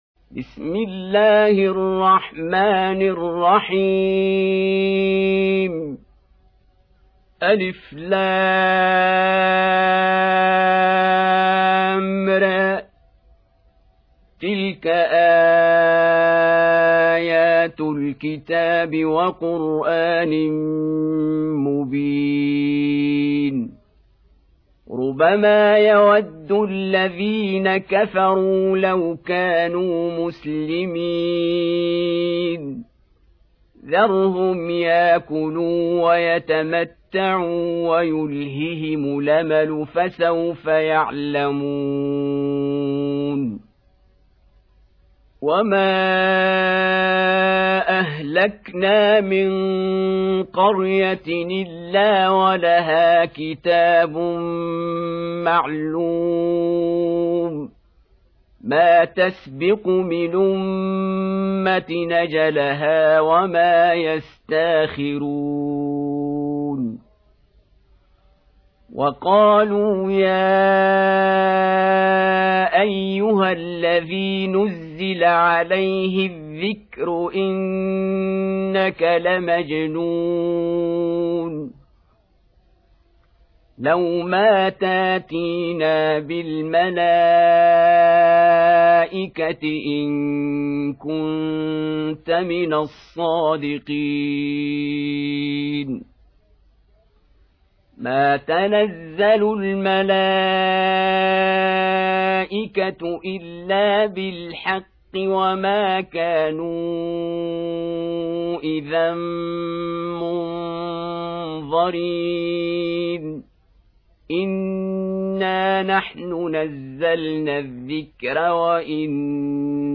15. Surah Al-Hijr سورة الحجر Audio Quran Tarteel Recitation
Surah Repeating تكرار السورة Download Surah حمّل السورة Reciting Murattalah Audio for 15.